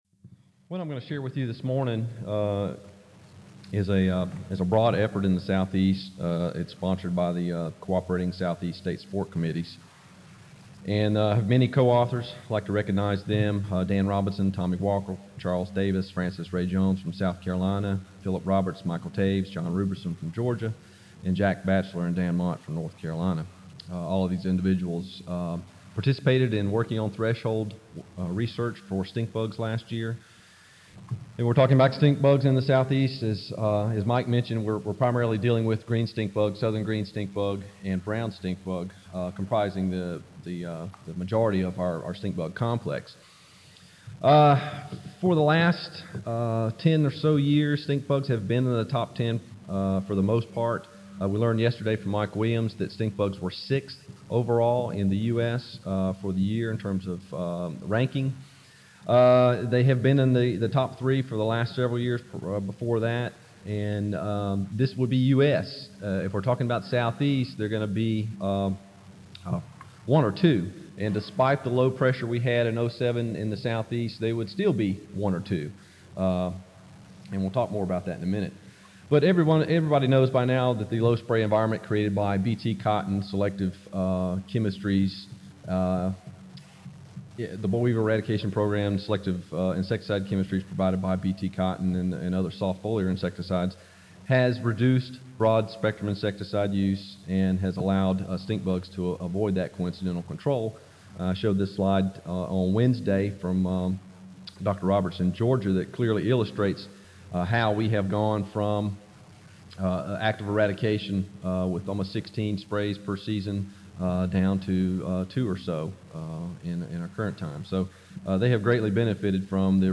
Refining Treatment Thresholds for Stink Bugs in the Southeast - 2007 Recorded presentation